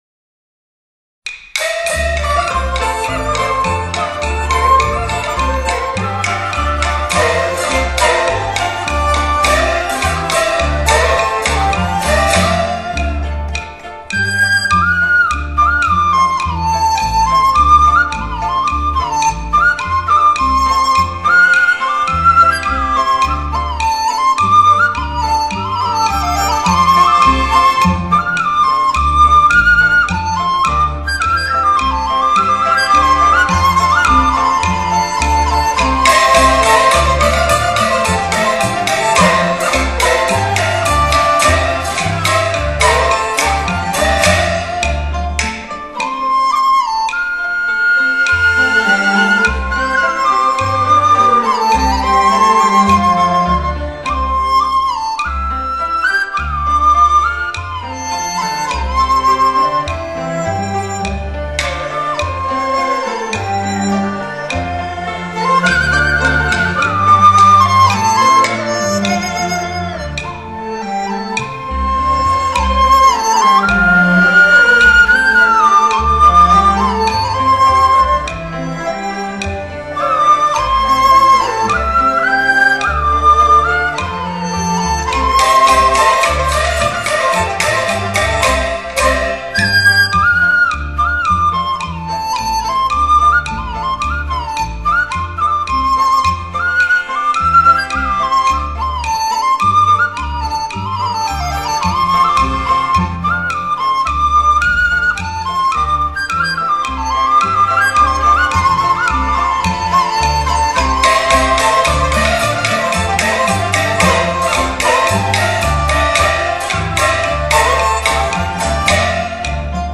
民族管弦乐合奏：它是由吹、拉、弹、打声四类乐器结合的大型合奏形式。
民族管弦集合奏的主要特点是音色丰富，音域宽广，表
日本DSD原装母带技术重新处理，迈向发烧天碟新纪元。